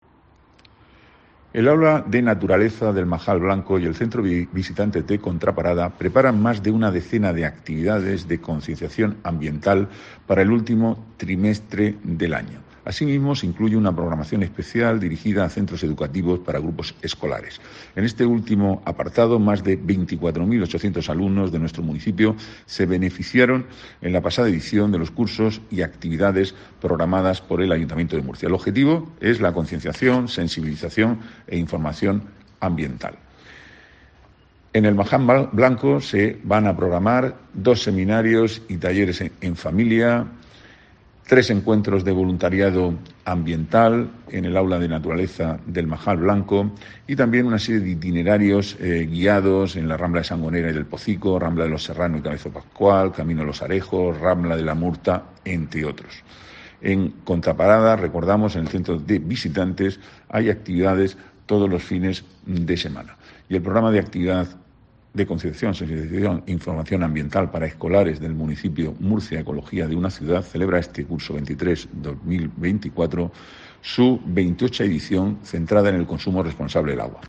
Antonio Navarro, concejal de Planificación Urbanística, Huerta y Medio Ambiente